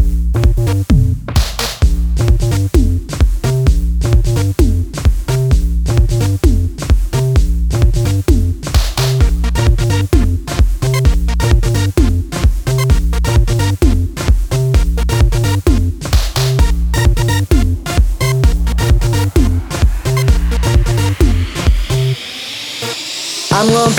for duet R'n'B / Hip Hop 3:15 Buy £1.50